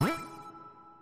sheepclick.mp3